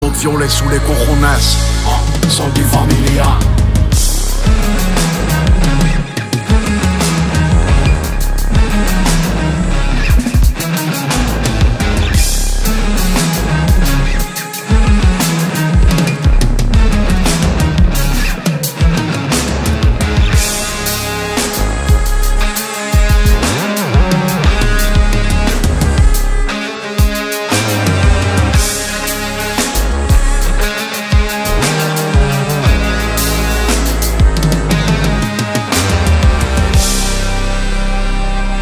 Categoria Hip Hop